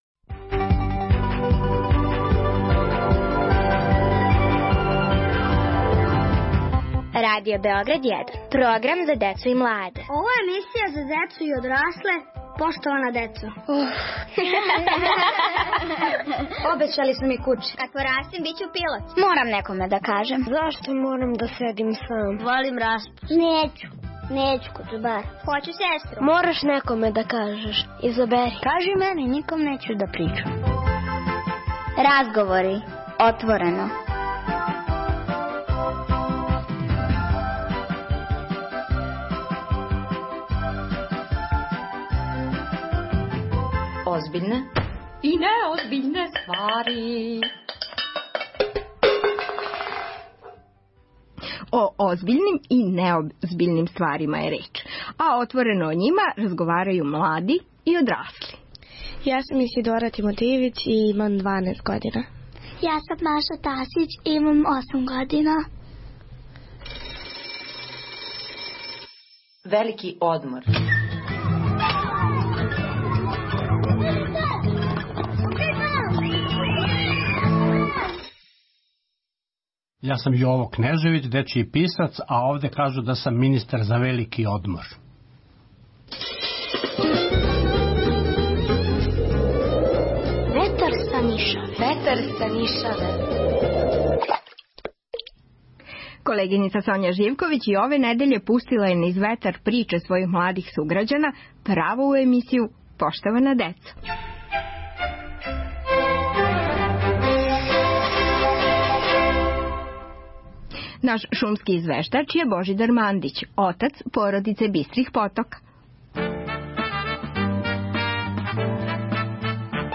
Разговори - отворено - тема: озбиљне и неозбиљне ствари.